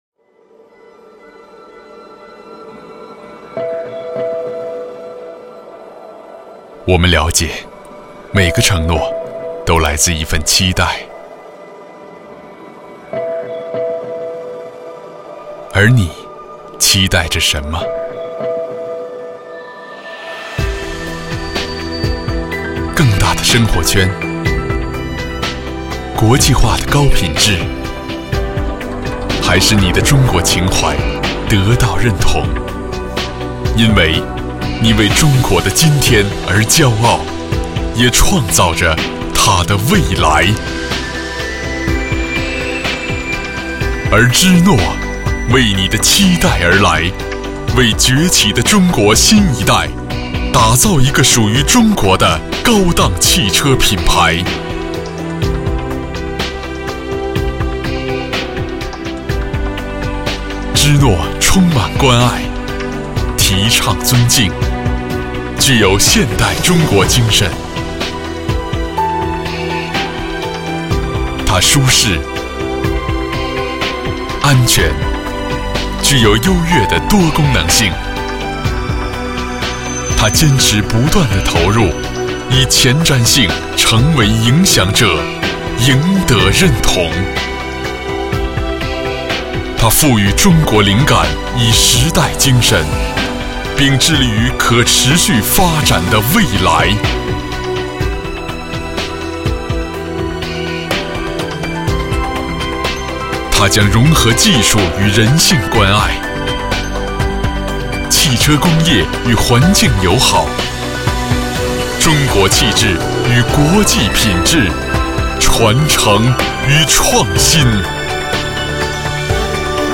• 男11 国语 男声 专题片_华晨宝马之诺汽车广告 大气浑厚磁性|沉稳|娓娓道来|科技感